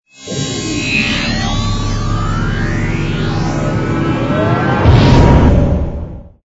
engine_bw_cruise_start.wav